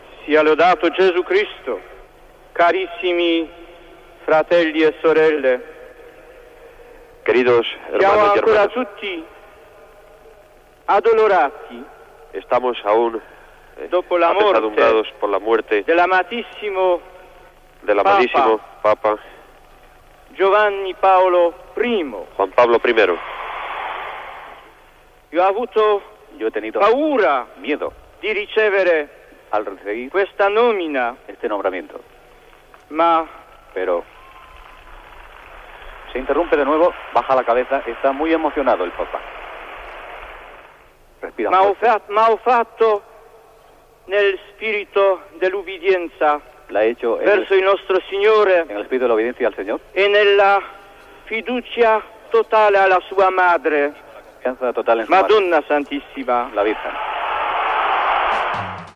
Primeres paraules del Sant Pare Joan Pau II.
Informatiu